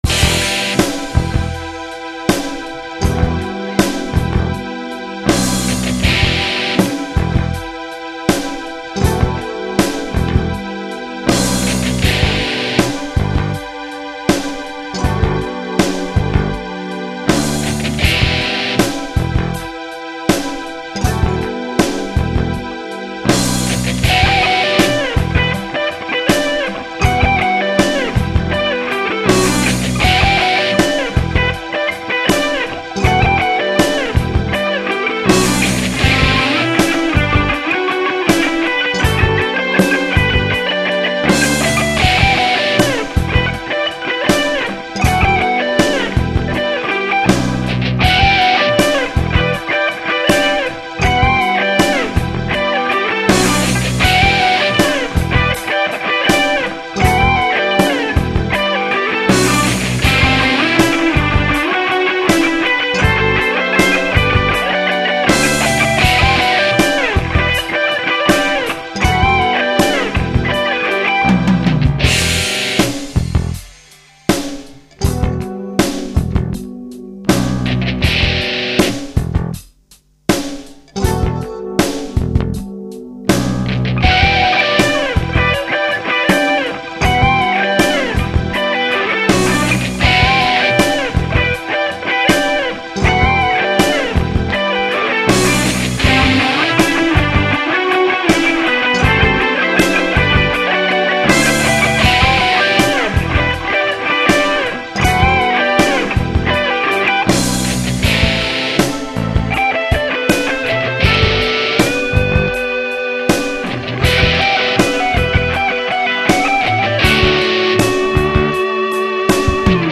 Annoyingly, it came out in Ab.
Following Vinny Burns's example, there are 20 to 24 tracks of backing vocals - three lines with eight tracks each in the pre-choruses, and five to six lines with four tracks each in the chorus, some climbing and others staying fairly static. Each set of four are panned two central, one each side.
The only thing that's changed is the insertion of the alternate-picked C arpeggio in the solo - in the original, it was a repeat of the little swept Am7 arpeggio used earlier. The backing vocals are also new, and everything's been freshly recorded this week.
This is also my September Collective piece, for which the assigment was “in an 80s style”.